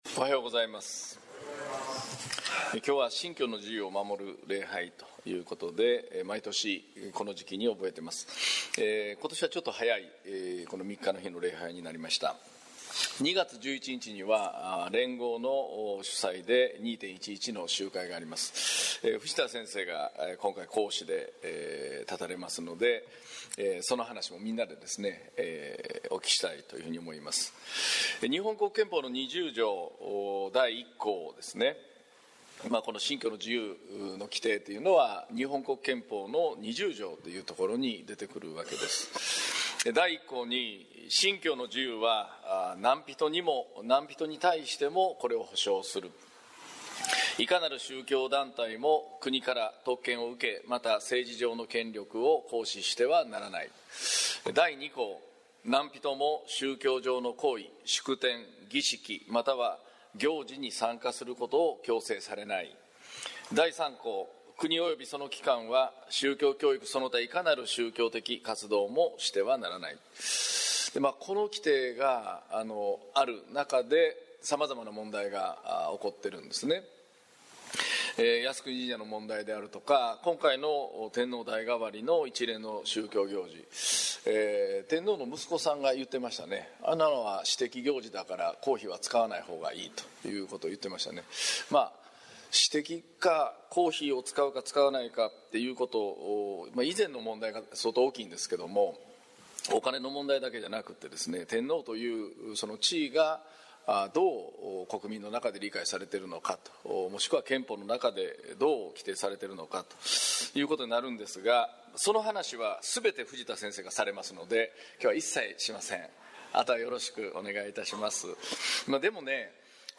2019年2月3日（日）信教の自由を守る礼拝 宣教題「愛が人を自由にする」 | 東八幡キリスト教会